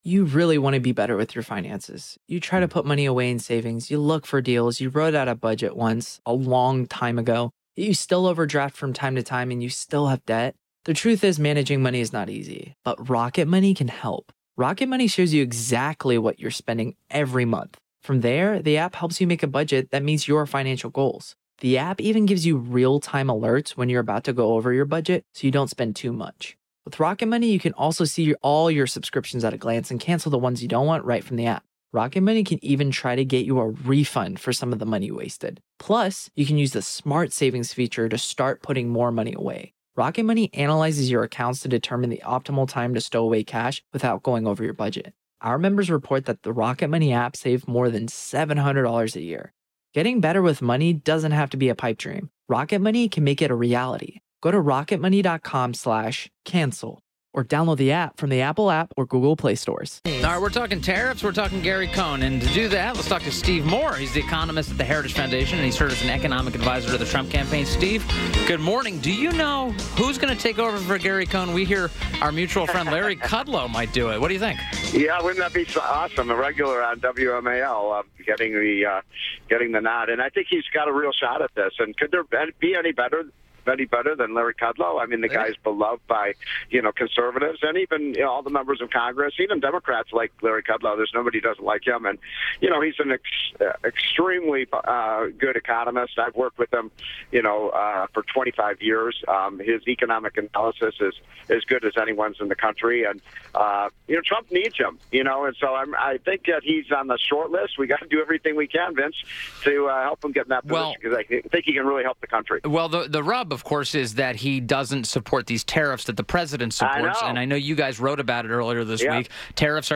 WMAL Interview - STEVE MOORE - 03.08.18